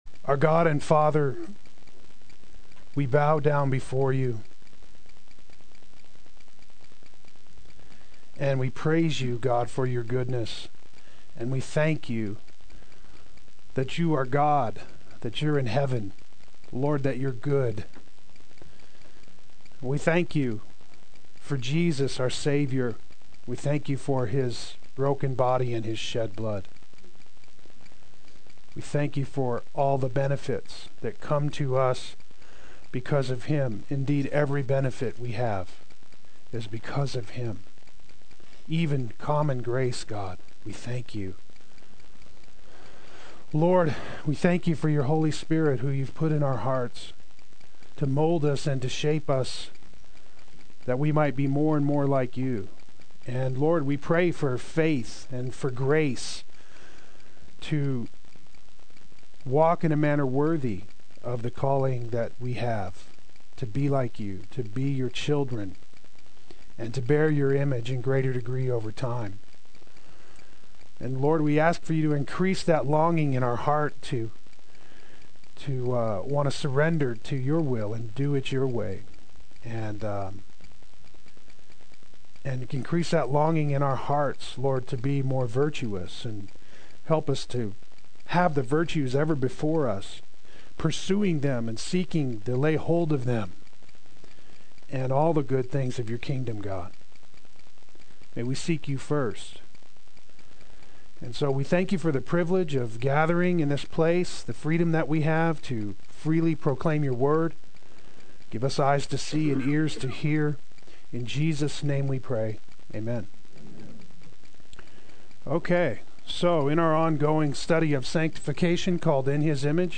Play Sermon Get HCF Teaching Automatically.
Thankfulness Adult Sunday School